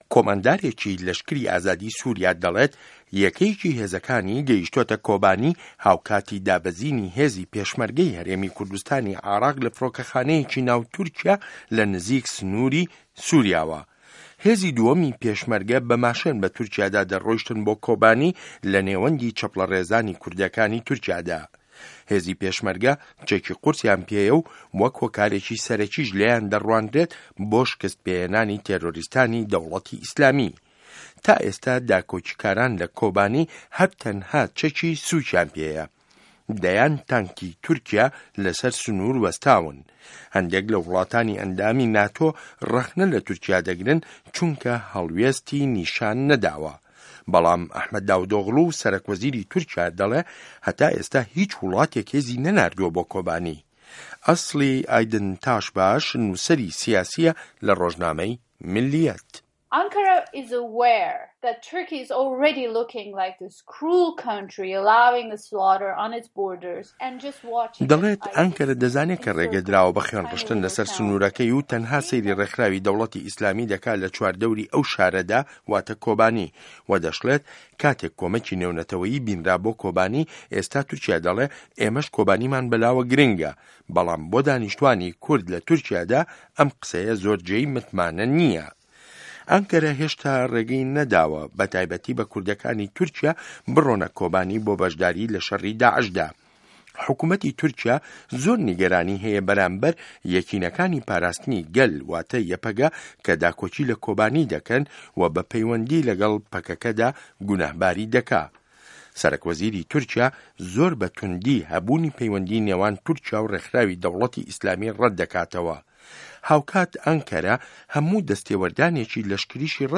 ڕاپـۆرتی تورکیا - سوریا - پـێشمه‌رگه‌